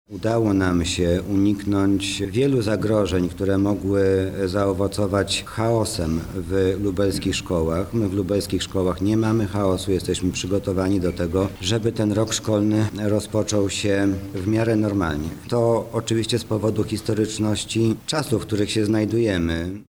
Jesteśmy przygotowani aby nowy rok szkolny rozpoczął się w dobrych warunkach pracy – mówi Mariusz Banach zastępca prezydenta Lublina do spraw oświaty